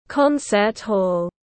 Phòng hòa nhạc tiếng anh gọi là concert hall, phiên âm tiếng anh đọc là /ˈkɒn.sət ˌhɔːl/.
Concert hall /ˈkɒn.sət ˌhɔːl/